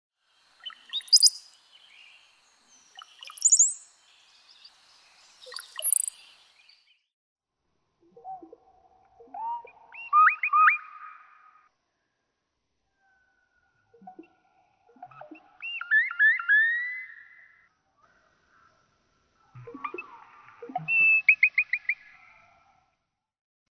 Brown-headed cowbird
♫126. Three songs excerpted from ♫125, played first at normal speed, then quarter speed. You can begin to hear the intricacies and contributions from the two voice boxes.
126_Brown-headed_Cowbird.mp3